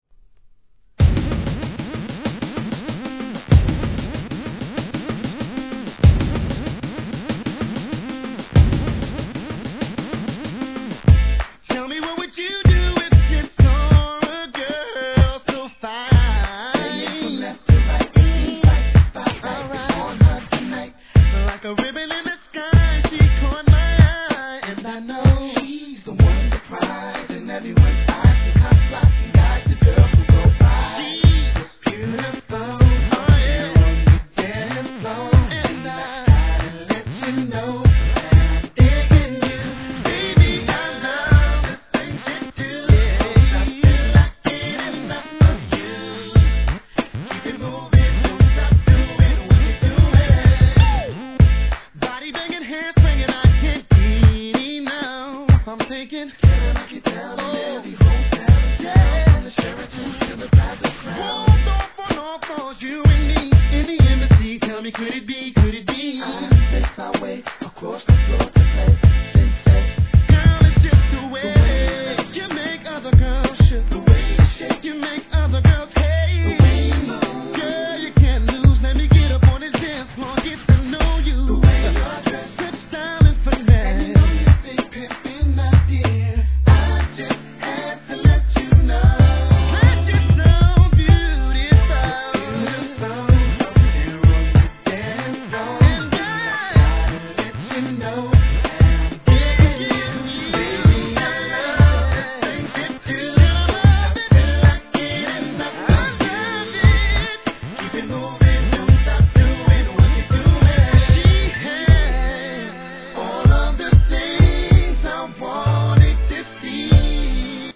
HIP HOP/R&B
CLUB栄えするバウンシーなBEATでの美声、メール・ヴォーカル♪途中まったりとメロディアスな展開からRAPの絡みも◎